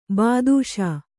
♪ bādūṣā